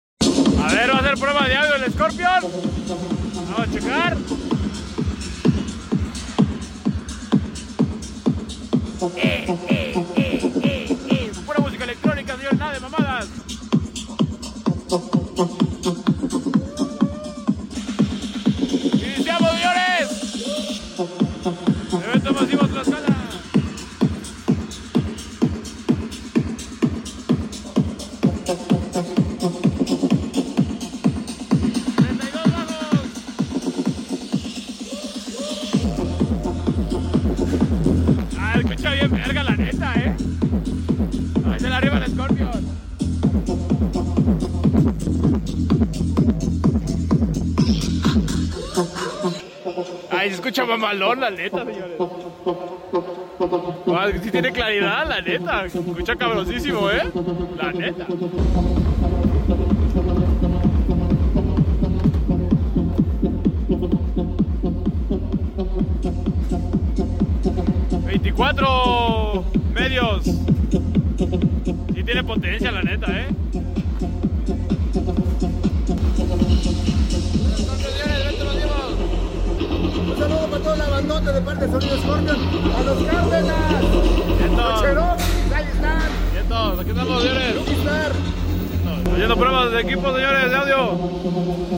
Electronica Musica Prueba De Audio